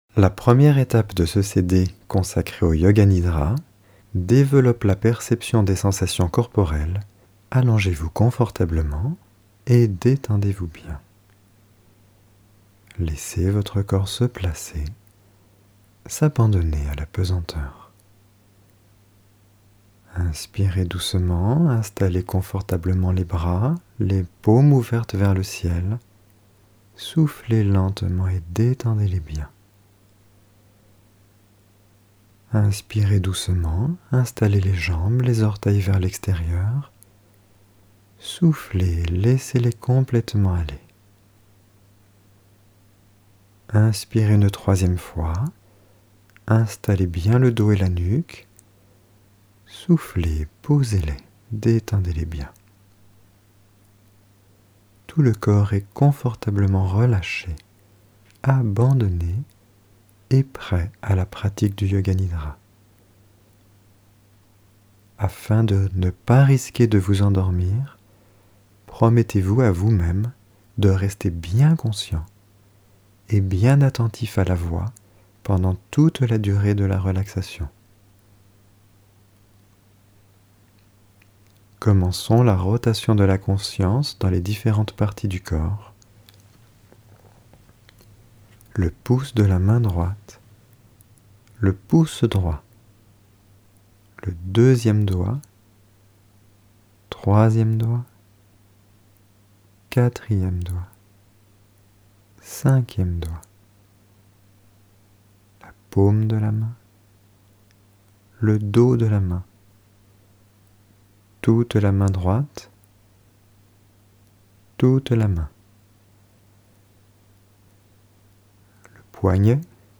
Genre : Meditative.